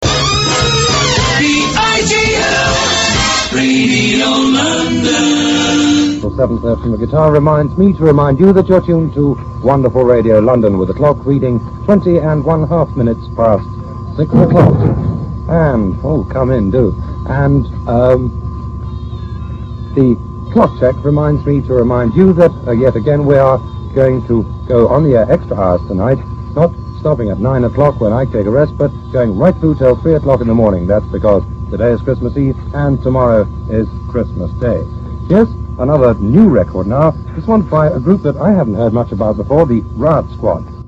Coming on air near Christmas 64, after the launch of Caroline, Radio London took its inspiration from American pop radio; the sort of Smooth Sailing radio the UK had missed out on. Tight playlists, great jingles and powerful, smiling presenters.